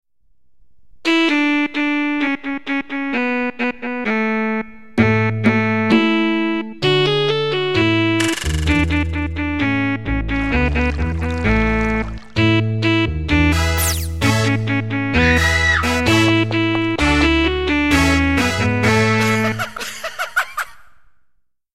a body percussion activity.